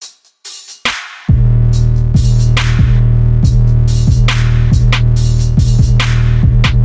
艰难的808，但听起来很爽
Tag: 140 bpm Trap Loops Drum Loops 1.15 MB wav Key : Unknown FL Studio